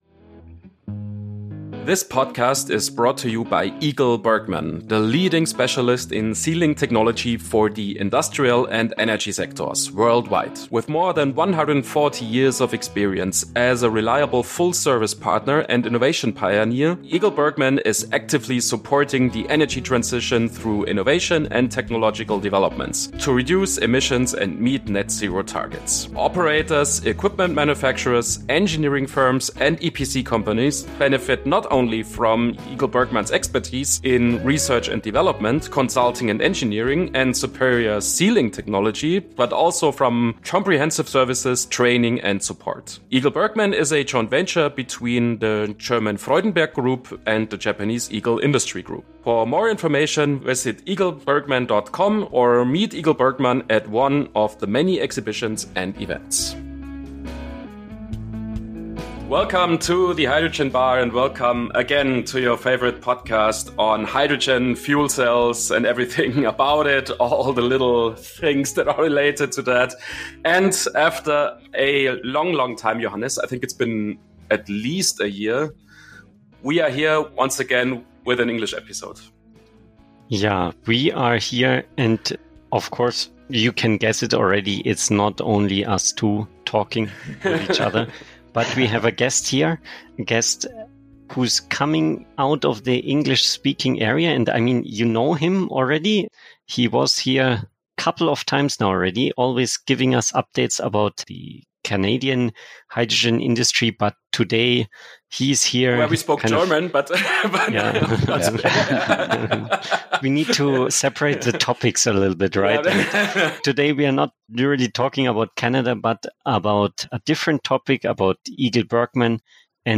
*** HINWEIS: Das Interview wurde auf Englisch geführt.*** Welcome to another episode of the Hydrogen Bar Podcast!